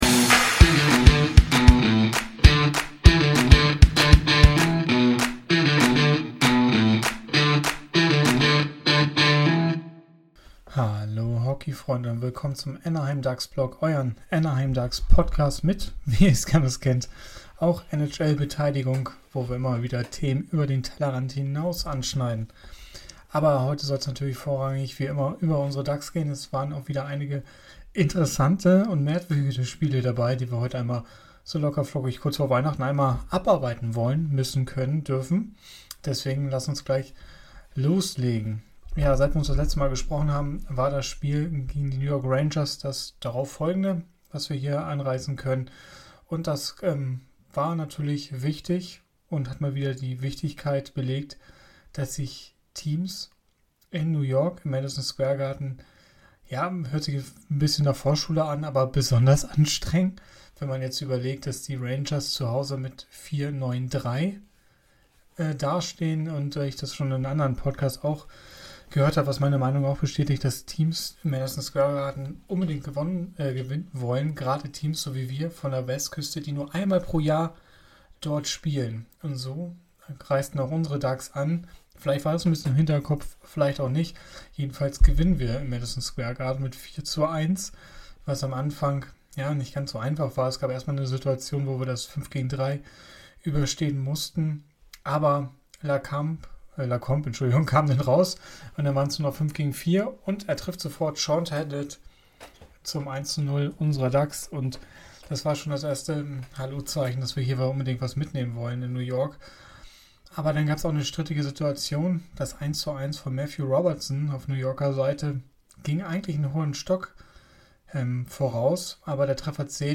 Hallo Hockeyfreunde, mit angeschlagener Stimme besprechen wir heute die jüngsten Spiele der Ducks und analysieren die aktuelle Situation. Dazu schauen wir genau auf die Tabelle und besprechen die Wichtigkeit der nächsten Spiele.